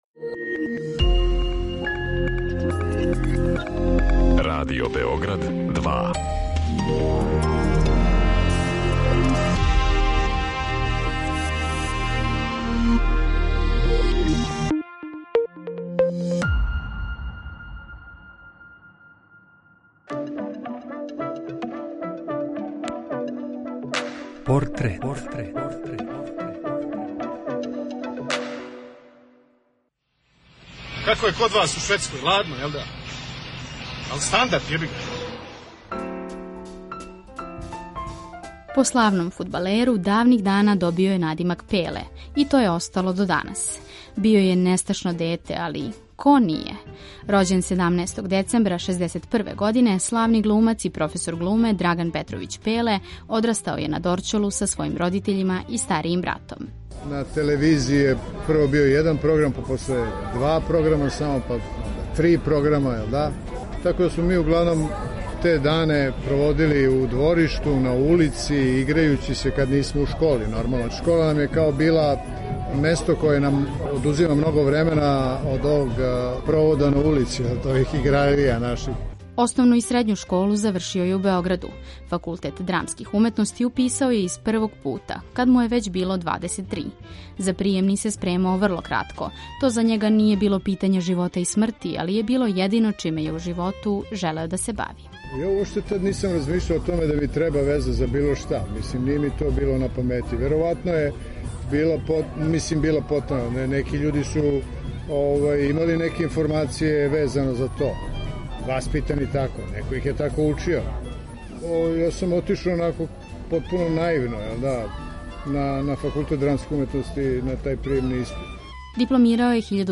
Приче о ствараоцима, њиховим животима и делима испричане у новом креативном концепту, суптилним радиофонским ткањем сачињеним од: интервјуа, изјава, анкета и документраног материјала. О портретисаним личностима говоре њихови пријатељи, најближи сарадници, истомишљеници…